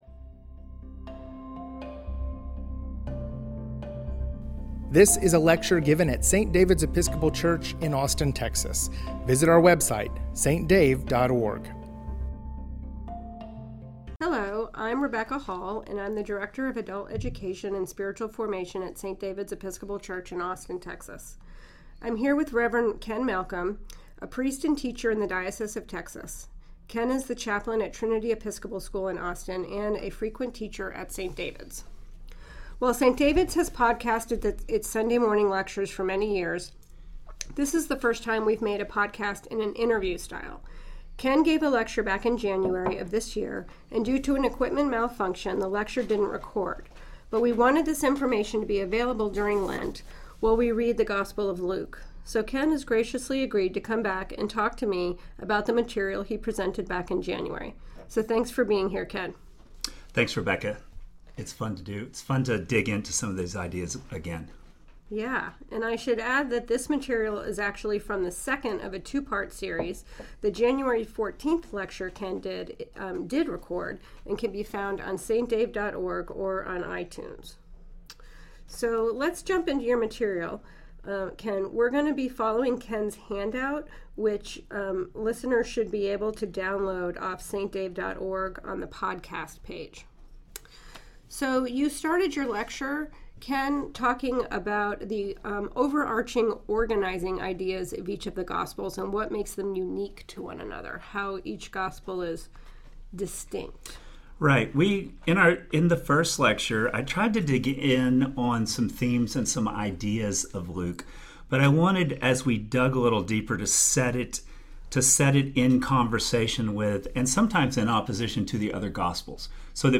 Gospel of Luke Overview Vol 2 interview